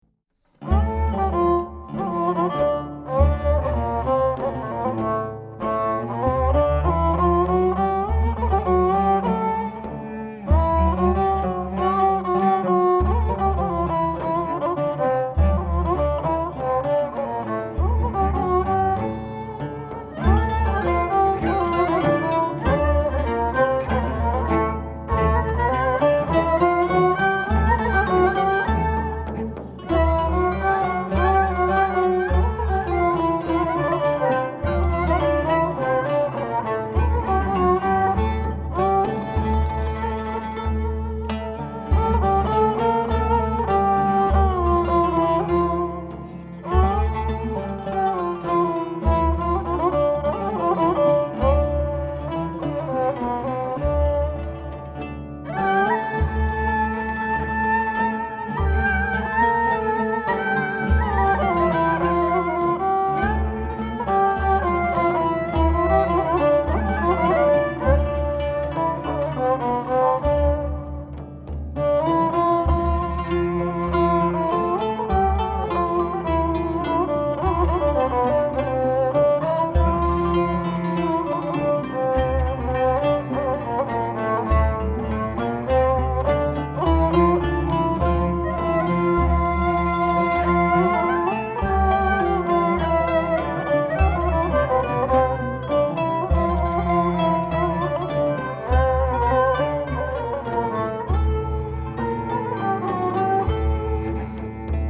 Genre: Turkish Traditional.
kemençe (left channel)
kemençe (right channel) & lavta
violoncello
kanun
daire
bendir
Recorded in November 1999 in Istanbul